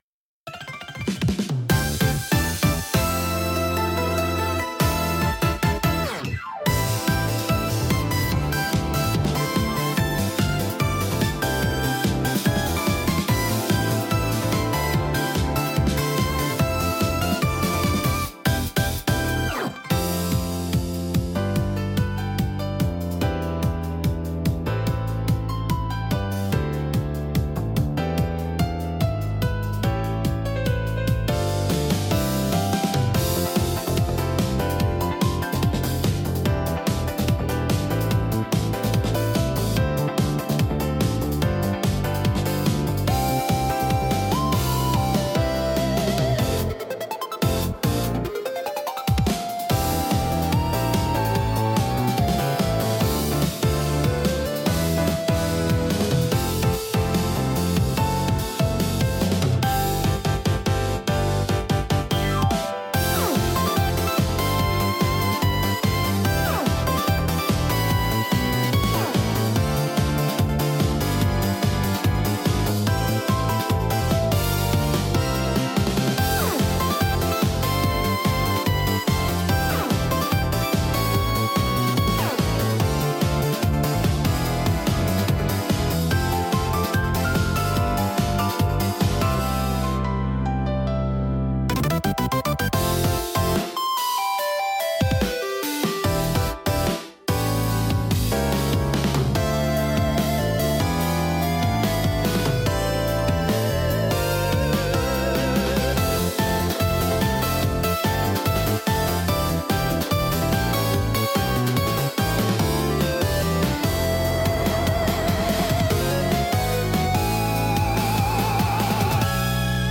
BGMセミオーダーシステムレースは、アップテンポで爽やかなシンセポップが主体の楽曲です。
明るく軽快なシンセサウンドとリズミカルなビートが疾走感を生み出し、スピード感あふれるエネルギッシュな空気を演出します。